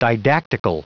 Prononciation du mot didactical en anglais (fichier audio)
Prononciation du mot : didactical